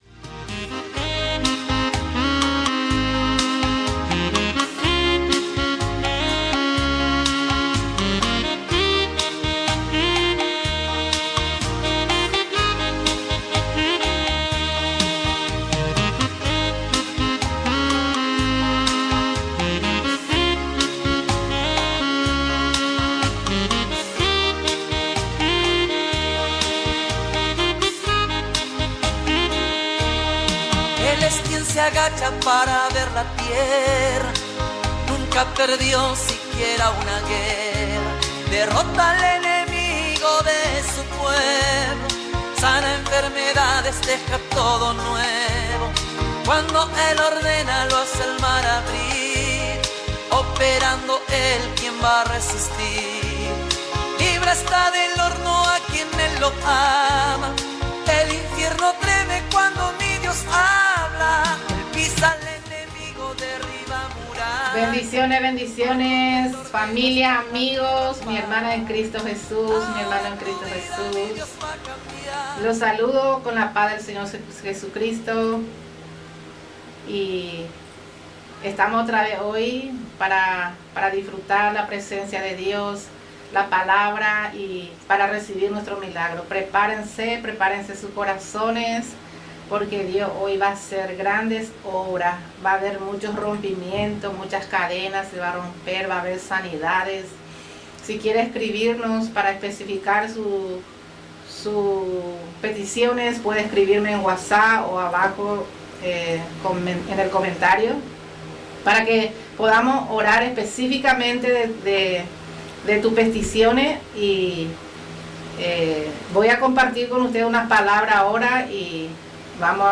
Presentació, lectura bíblica i prec religiós
Religió